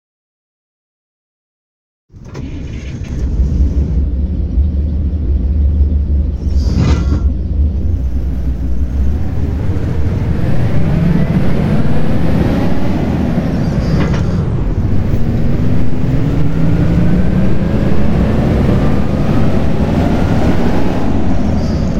Шум мотора и переключение скоростей в автобусе ПАЗ при движении